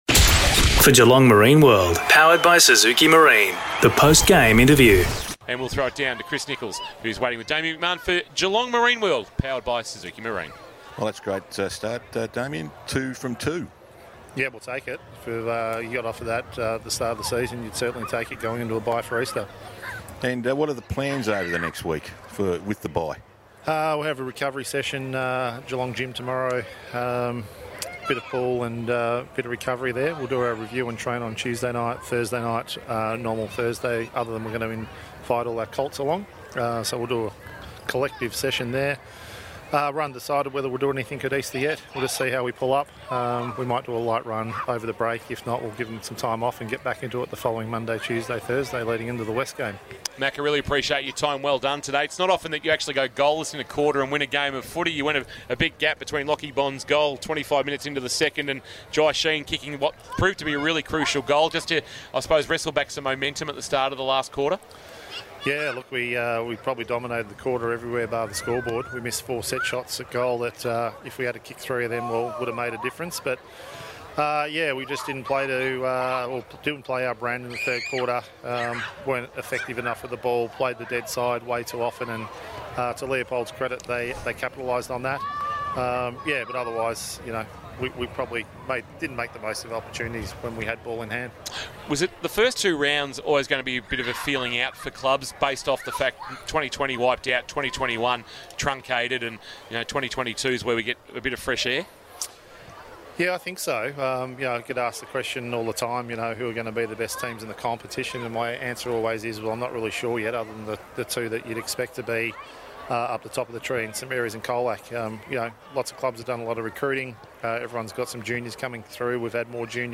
2022 - GFL ROUND 2 - NEWTOWN & CHILWELL vs. LEOPOLD: Post-match Interview